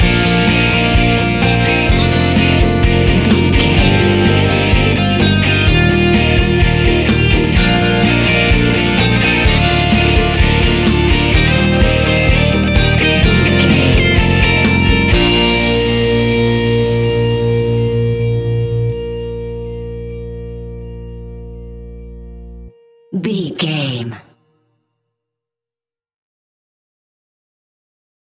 Uplifting
Ionian/Major
B♭
indie pop
energetic
cheesy
instrumentals
guitars
bass
drums
piano
organ